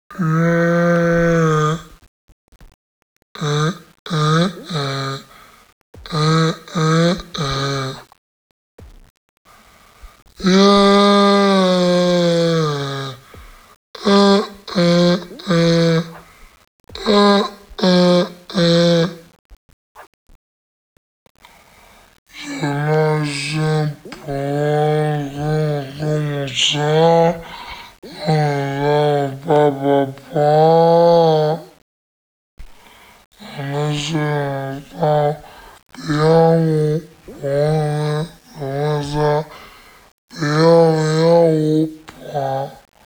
Funny sound effects
ehh_eh_eh_eh.wav